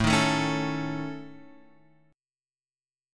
AMb5 chord {x 0 1 2 2 x} chord